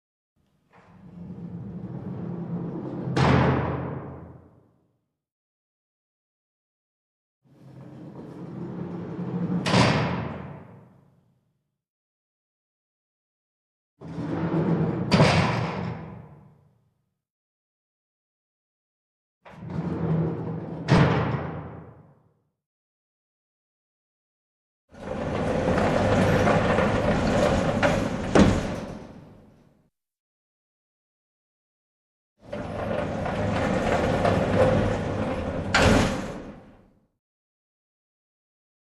ДВЕРЬ, ТЮРЕМНЫЙ ЗАСОВ , КАМЕРА
• Категория: Дверные звуки
• Качество: Высокое